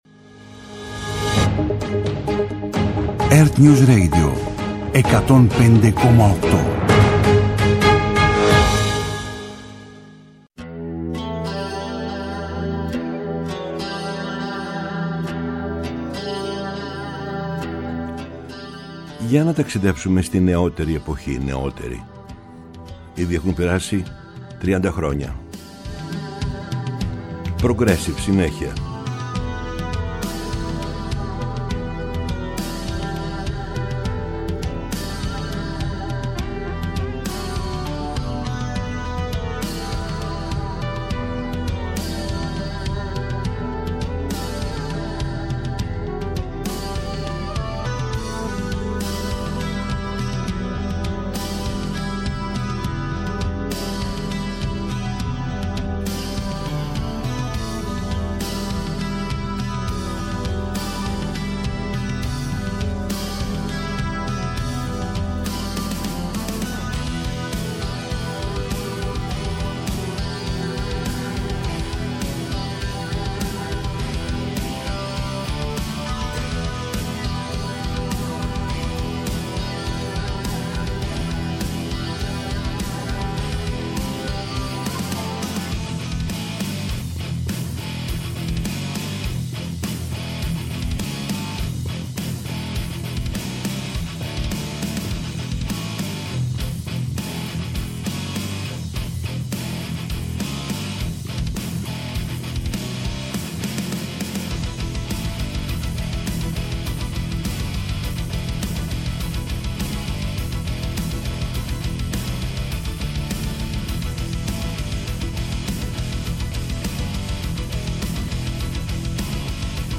progressive rock